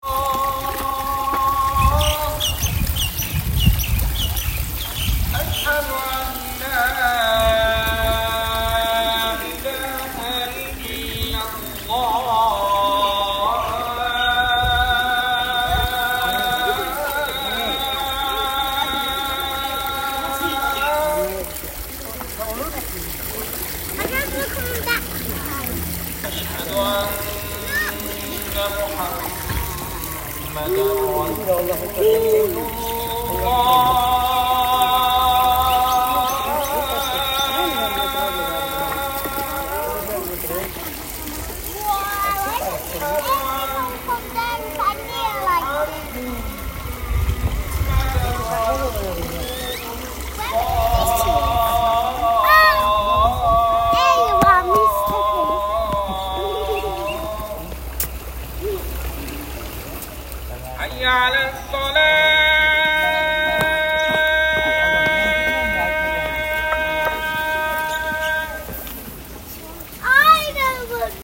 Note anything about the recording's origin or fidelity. This is the sound of a midday call to prayer at the Zangi Ota Memorial Complex. The complex, located just outside Tashkent (20 km), is very popular pilgrimage site as it houses Zangi Ota and Anbar bibi mausoleums.